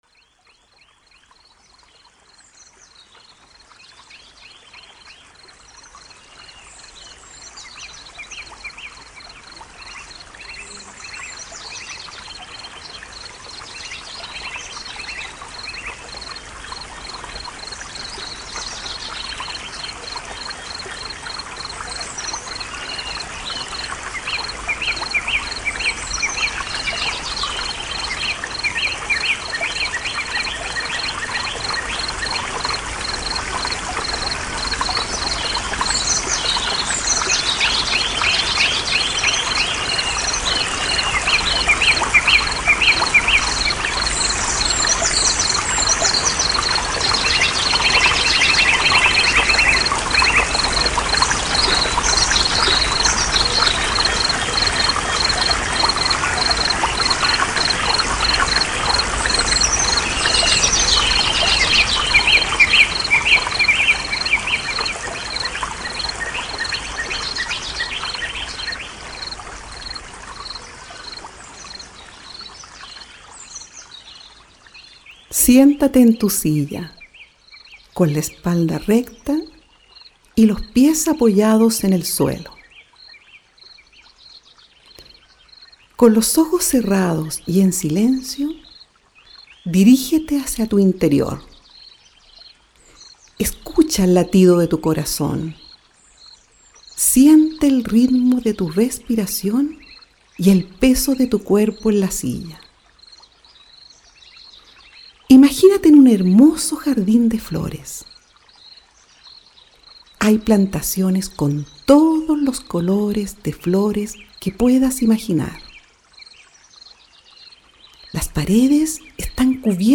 Sonidos de la naturaleza
Audio con sonidos de la naturaleza.